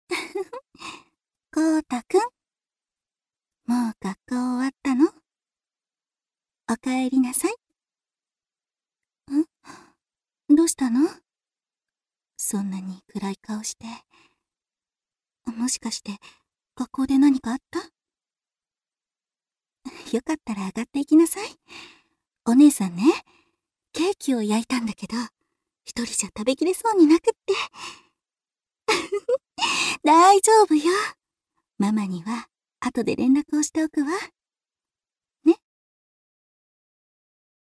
アトリエピーチのサンプルボイス一覧および紹介
大人しい